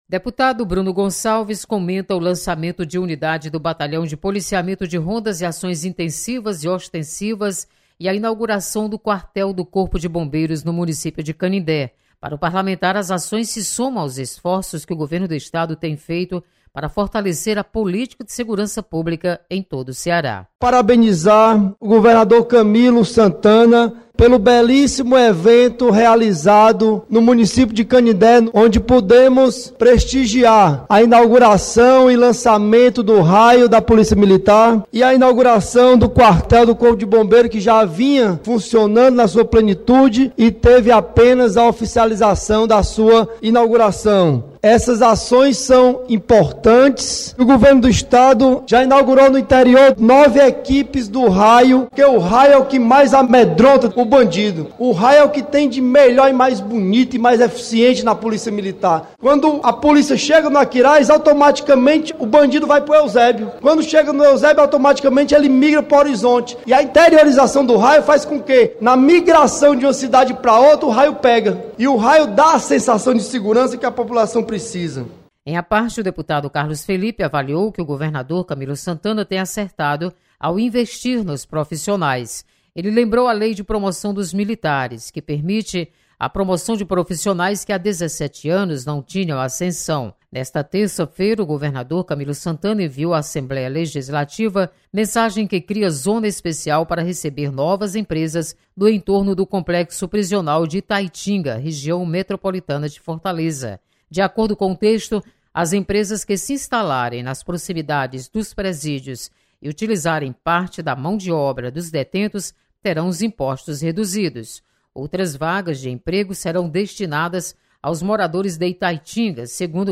Você está aqui: Início Comunicação Rádio FM Assembleia Notícias Plenário